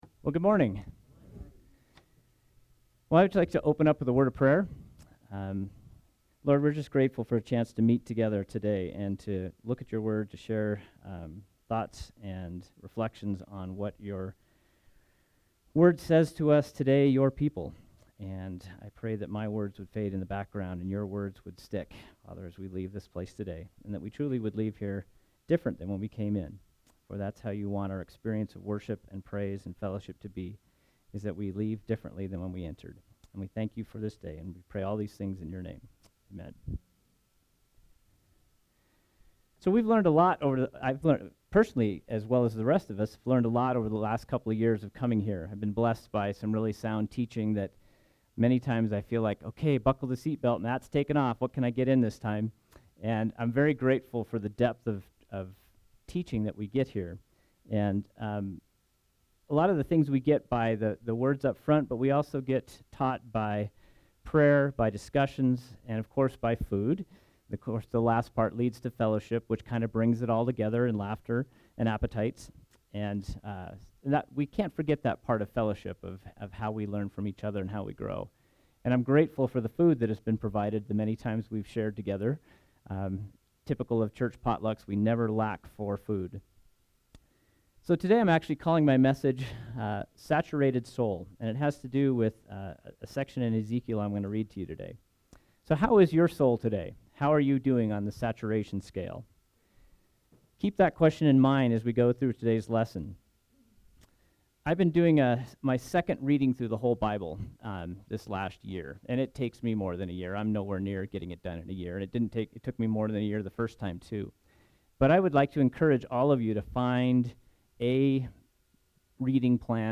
SERMON: Go Deeper – Church of the Resurrection